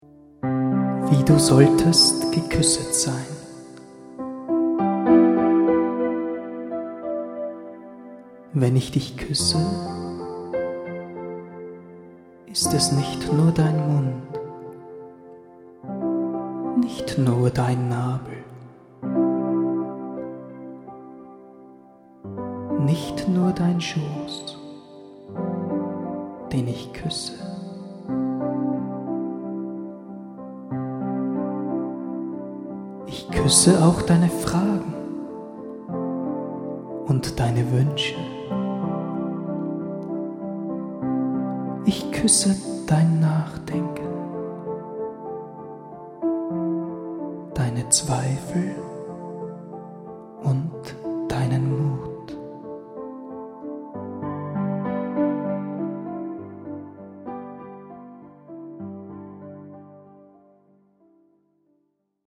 Eine musikalische Dichterlesung